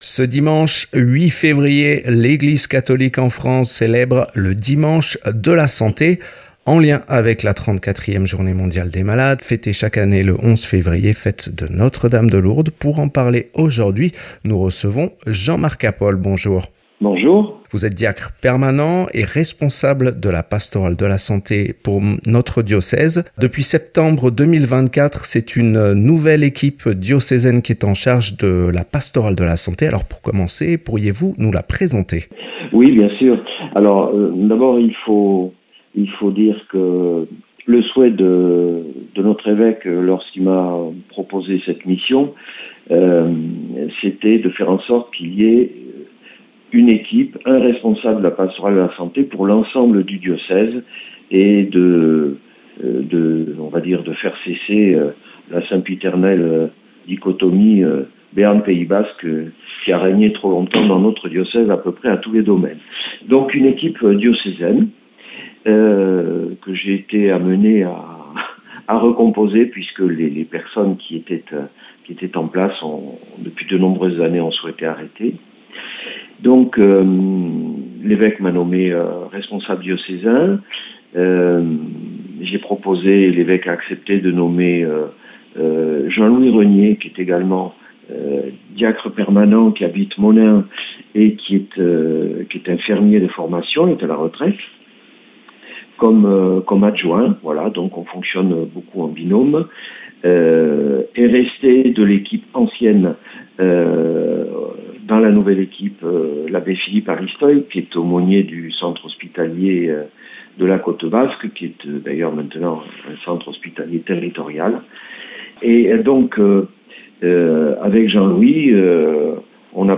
Interviews et reportages